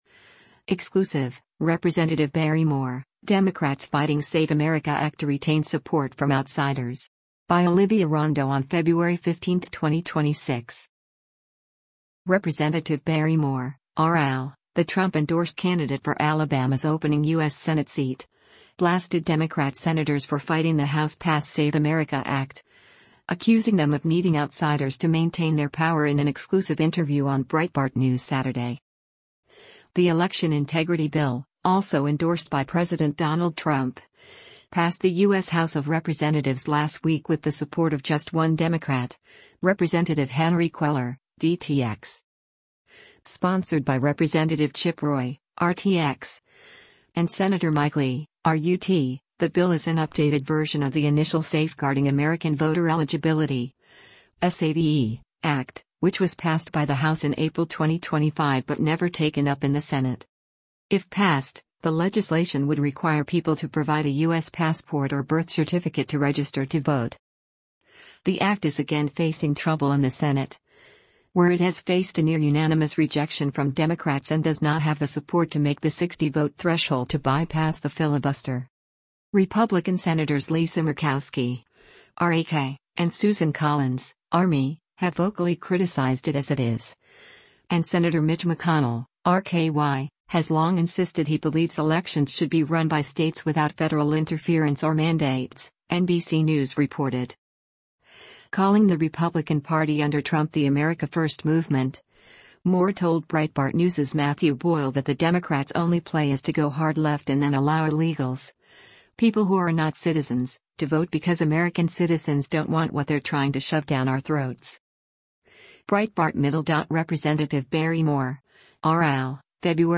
Rep. Barry Moore (R-AL), the Trump-endorsed candidate for Alabama’s opening U.S. Senate seat, blasted Democrat senators for fighting the House-passed SAVE America Act, accusing them of needing “outsiders” to maintain their power in an exclusive interview on Breitbart News Saturday.